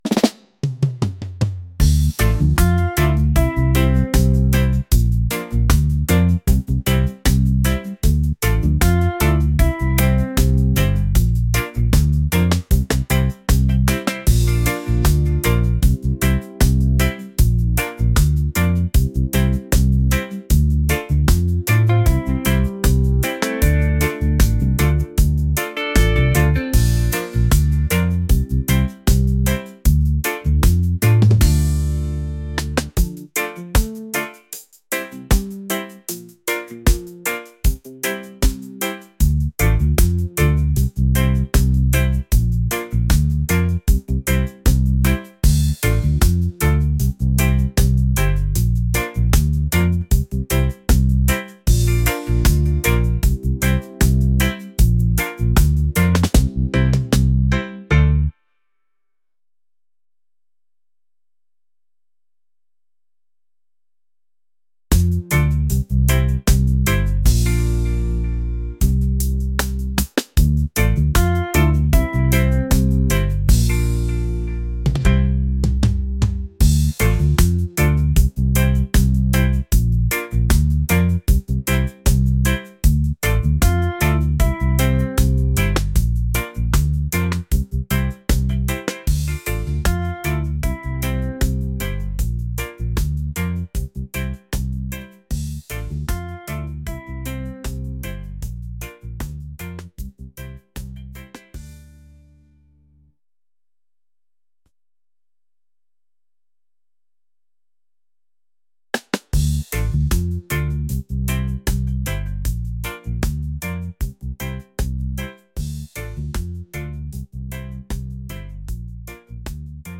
smooth | laid-back | reggae